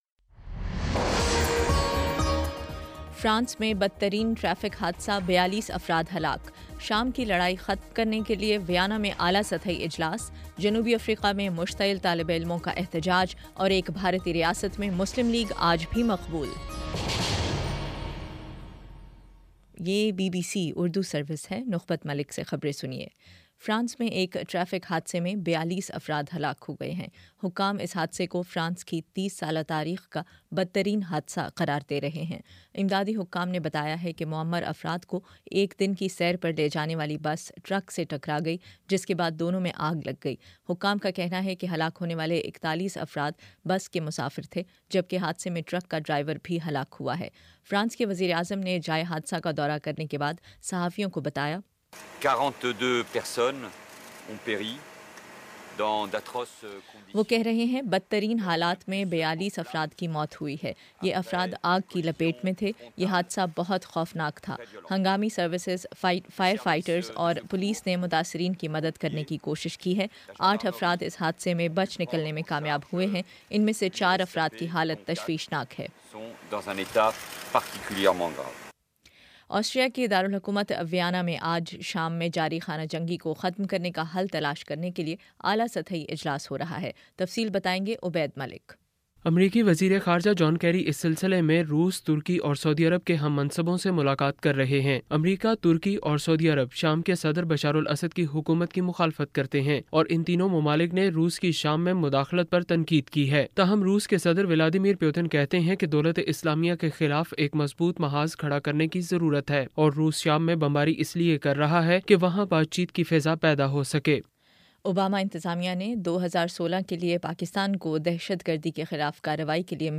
اکتوبر 23 : شام چھ بجے کا نیوز بُلیٹن